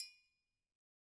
Triangle6-HitFM_v1_rr2_Sum.wav